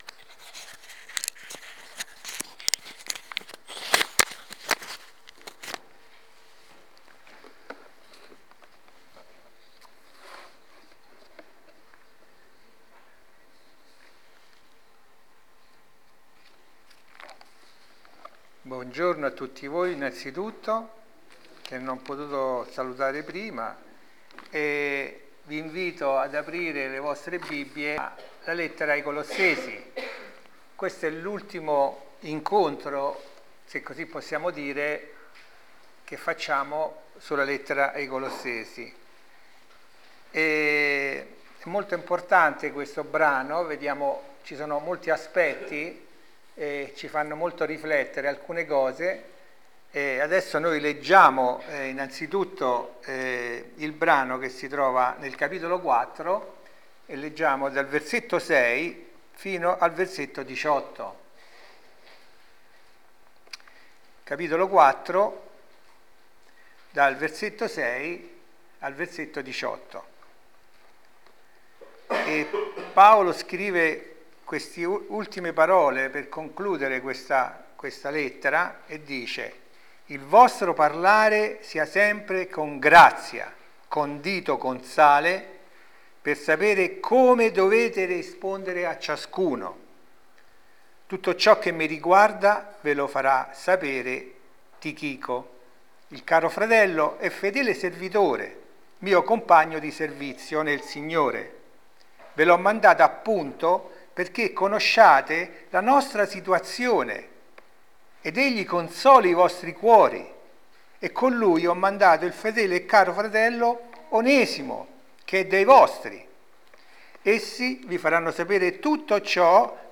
Insegnamenti dal passo di Colossesi 4:6-18